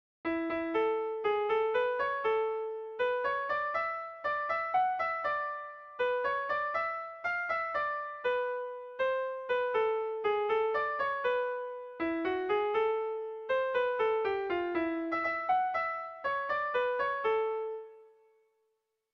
Erromantzea
ABDE